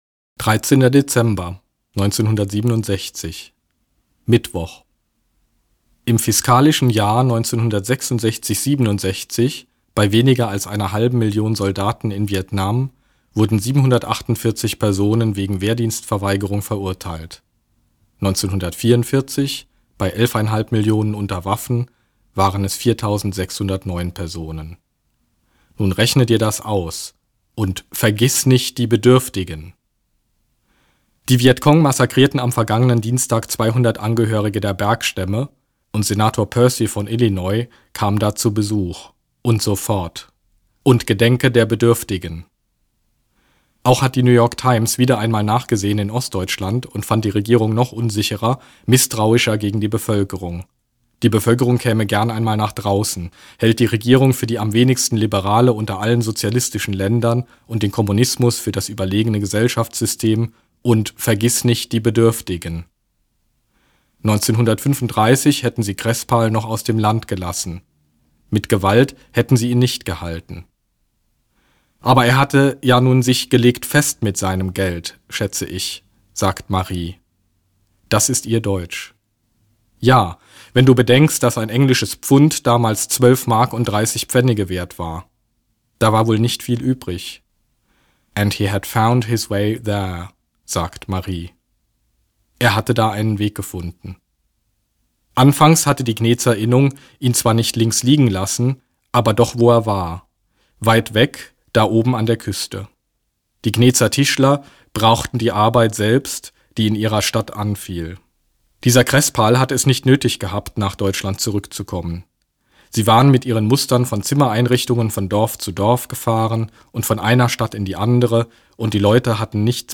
Als Referent auf der 3. Internationalen Uwe Johnson-Tagung in diesem Jahr war die Gelegenheit günstig, statt der angebotenen Hafenrundfahrt lieber einen Termin im Tonstudio wahrzunehmen.